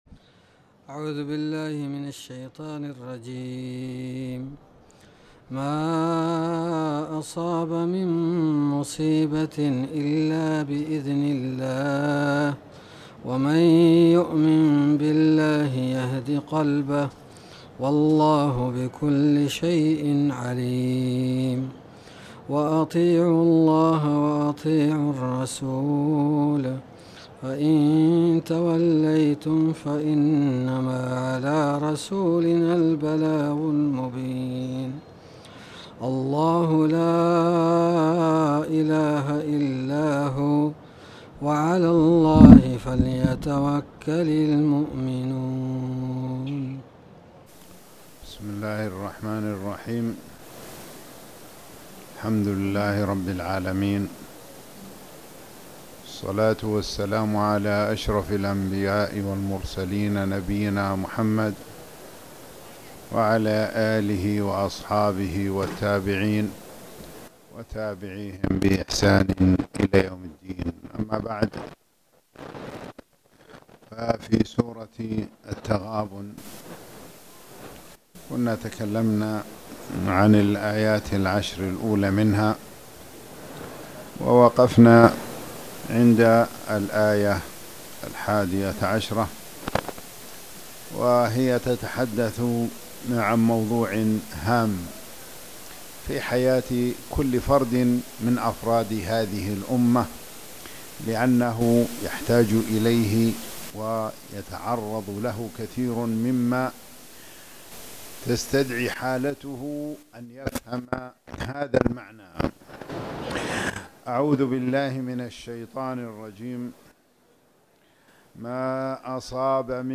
تاريخ النشر ١٩ رجب ١٤٣٨ هـ المكان: المسجد الحرام الشيخ